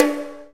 Index of /90_sSampleCDs/Roland - Rhythm Section/KIT_Drum Kits 3/KIT_Reggae Kit 1
TOM REGGAE0B.wav